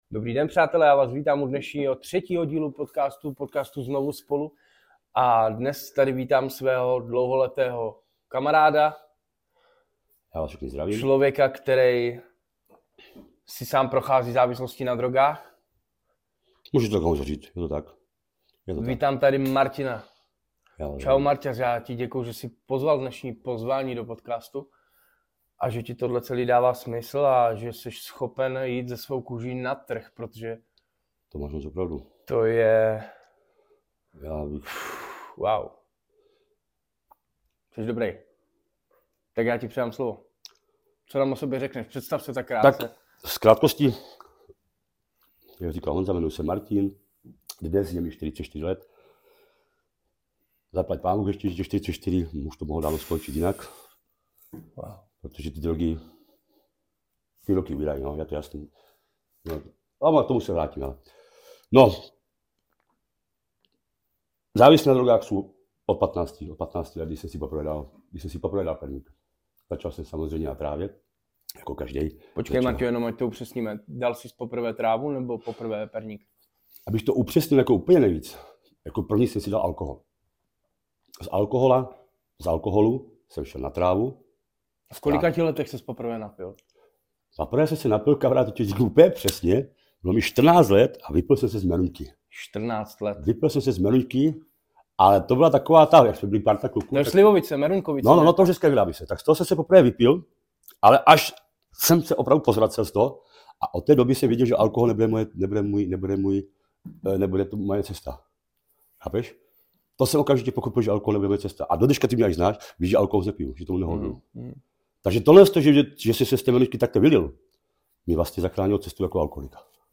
rozhovor dvou kamarádů - ZNOVU SPOLU